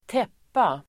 Uttal: [²t'ep:a]